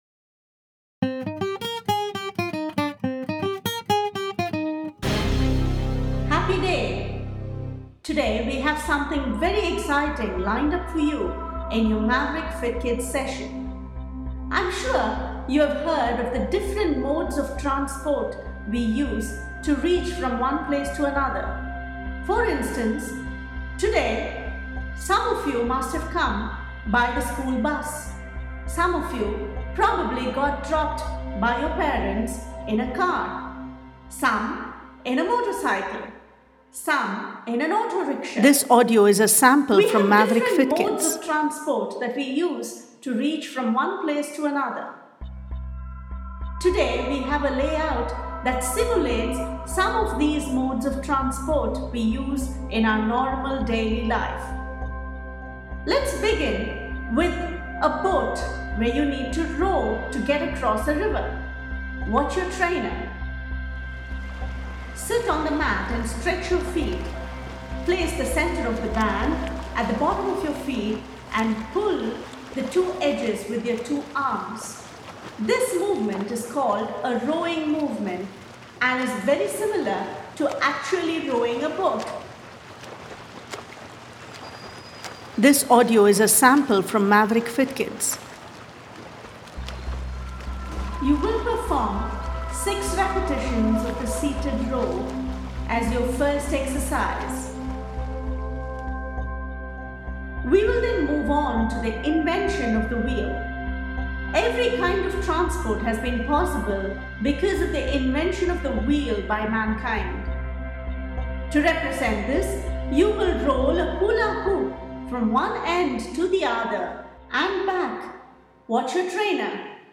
Demonstrate the above with the instruction audio provided below: